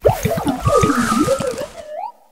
arboliva_ambient.ogg